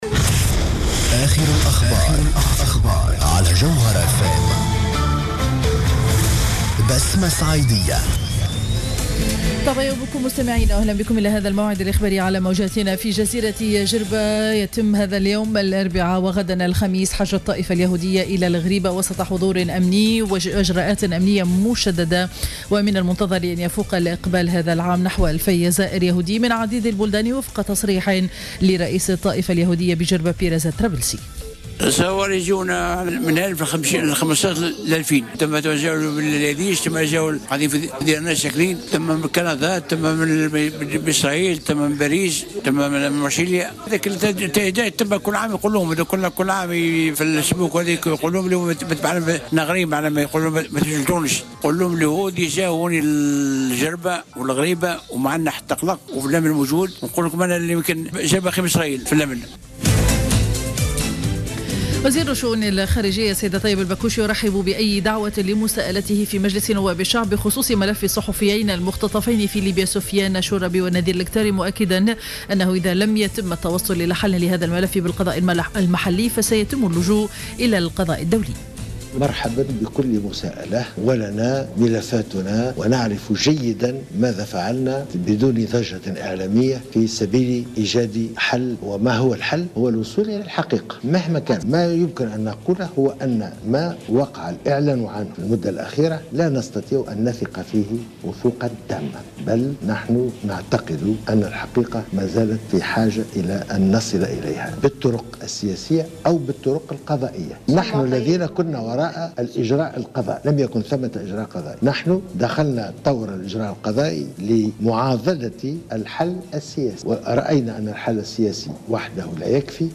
نشرة أخبار السابعة صباحا ليوم الإربعاء 6 ماي 2015